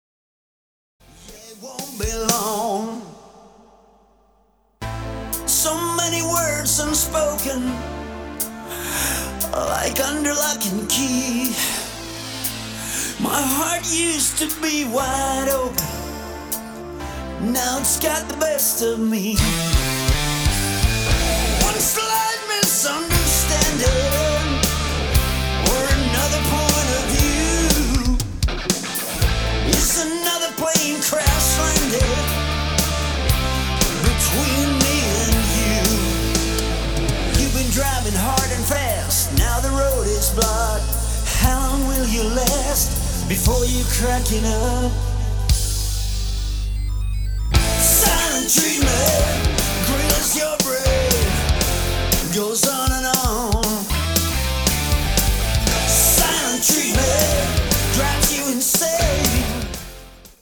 lead and rhythm guitar
lead and background vocals, rhythm guitar, percussion
Keyboards
Bass
Drums
Melodic Rock/AOR
exciting hard melodic rock